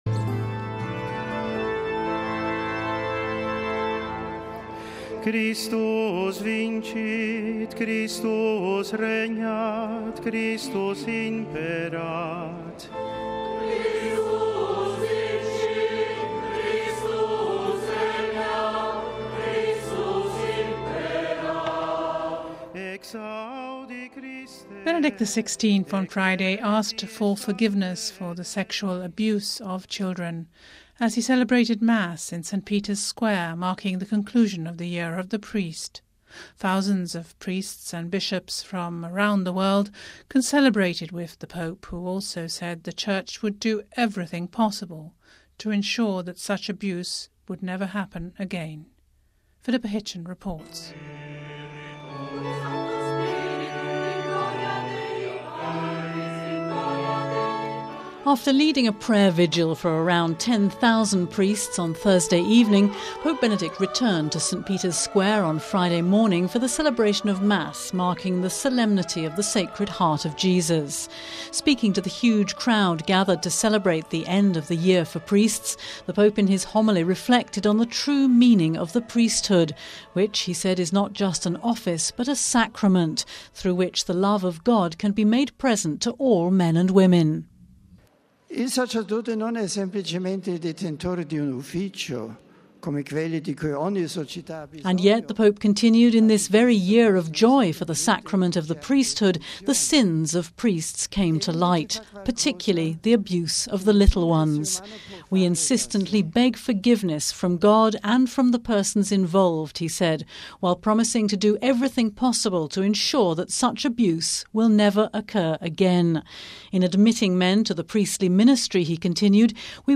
Pope Benedict on Friday asked for forgiveness for the sexual abuse of children as he celebrated Mass in St Peter's Square marking the conncluson of the year for priests.